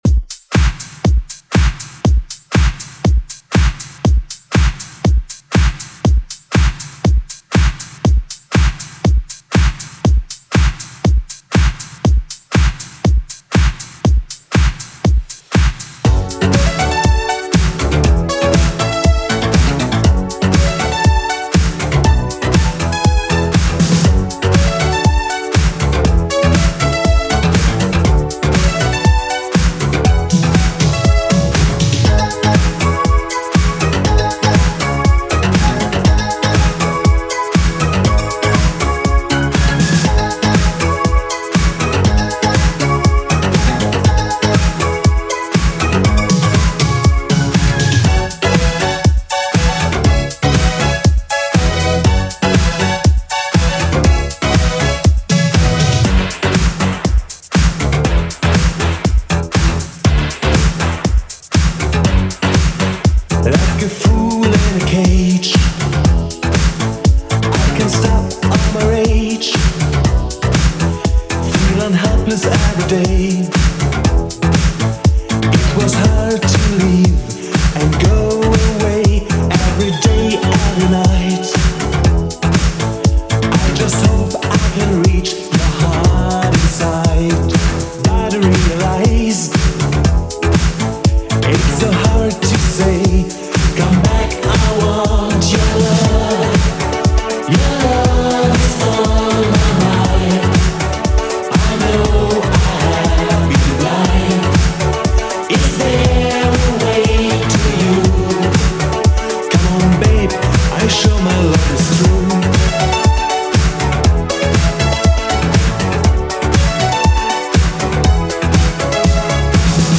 Genre: 80's
BPM: 120